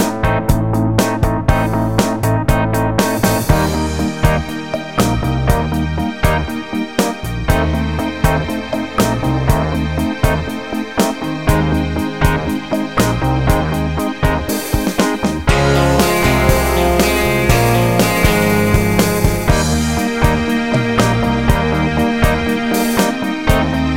No Saxophone Country (Female) 3:55 Buy £1.50